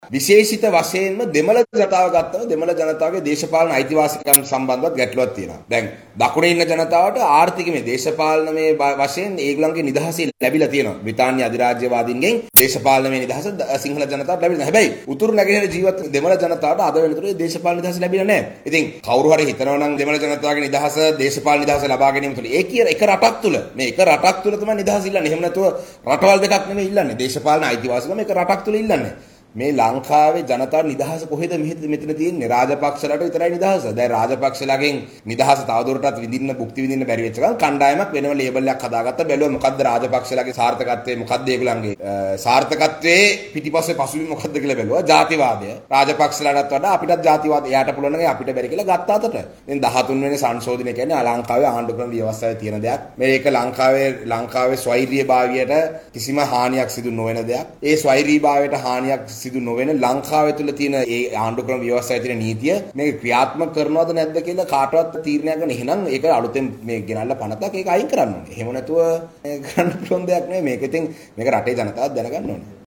අද පැවති මාධ්‍ය හමුවකට එක්වෙමින් පාර්ලිමේන්තු මන්ත්‍රී ශානක්‍යම් රාසමානික්කම් මහතා මෙම අදහස් පල කළා.